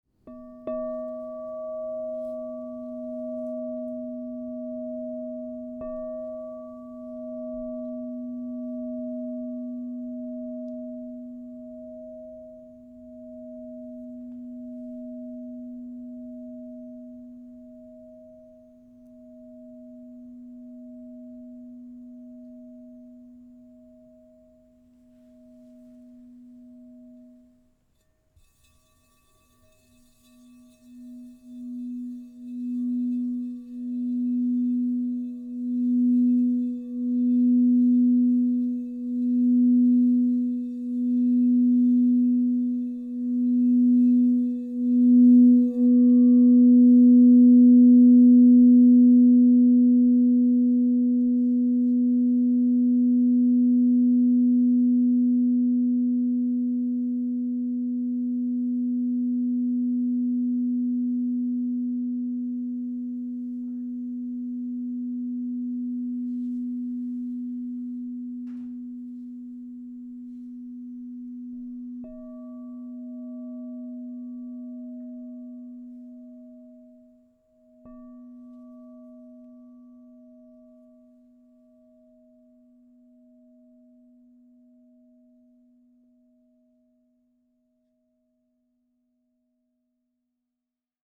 Palladium 10″ B -5 Crystal Tones Singing Bowl
At 10 inches in size, tuned to the note of B -5, this instrument resonates with the crown chakra, awakening connection to higher consciousness, divine wisdom, and inner stillness.
The tones of Palladium are both soothing and commanding—bringing balance where there is chaos, cool clarity where there is overwhelm, and serenity where there is restlessness.
432Hz (-), 440Hz (TrueTone)